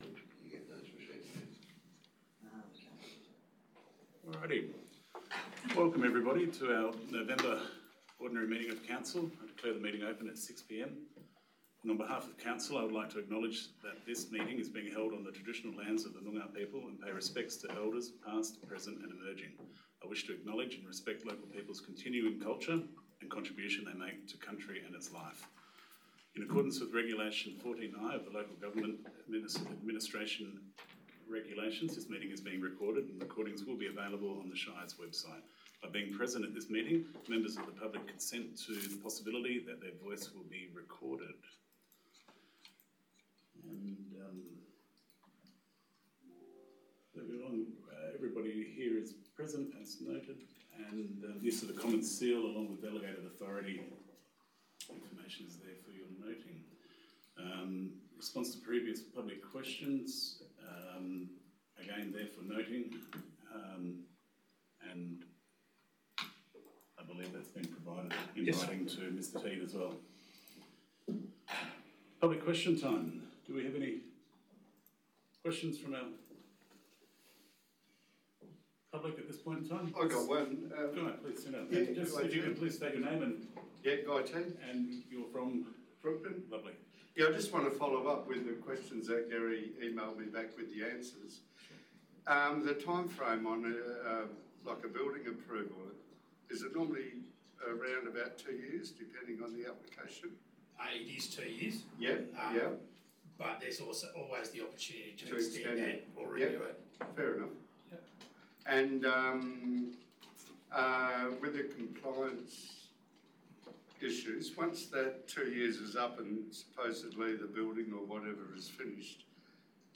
20 November 2025 Ordinary Meeting of Council Recording (27.88 MB)